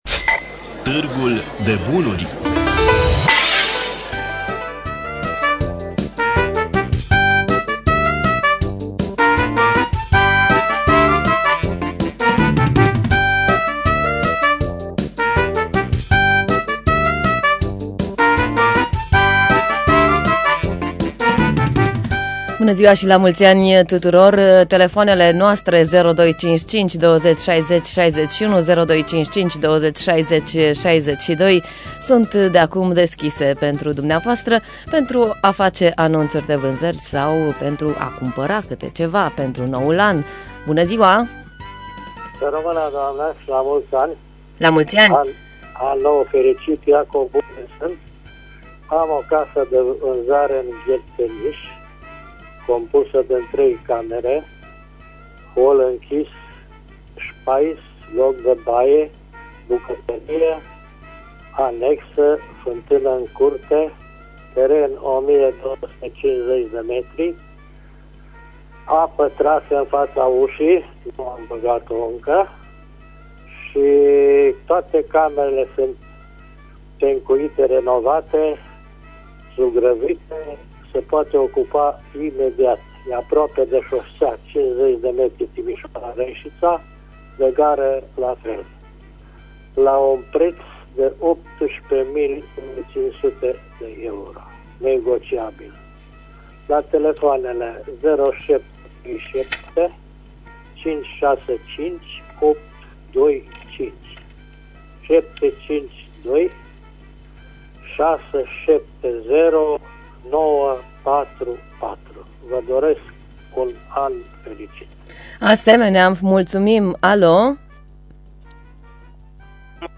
Înregistrarea emisiunii „Târgul de bunuri” de luni 05.01.2015 difuzată la Radio România Reşiţa.